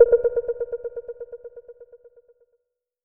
Destroy - EffectPerc.wav